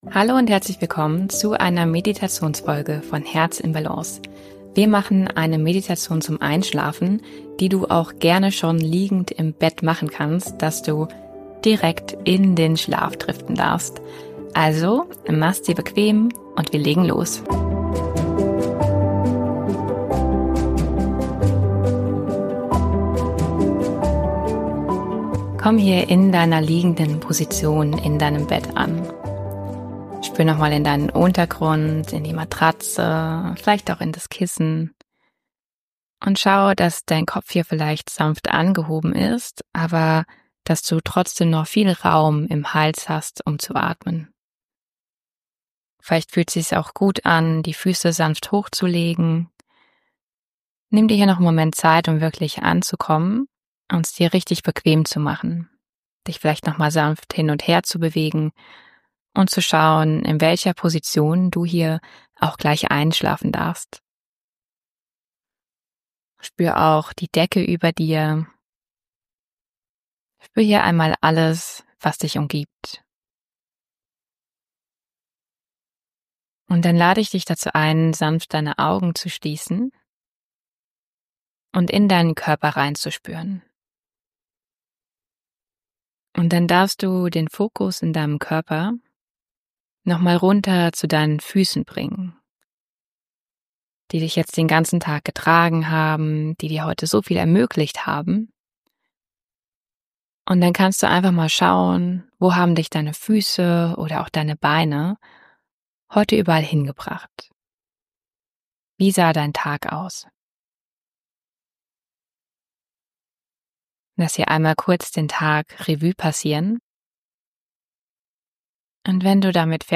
Dann leg dich ins Bett, mach die Meditation und lass mich dir beim Einschlafen helfen!